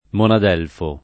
[ monad $ lfo ]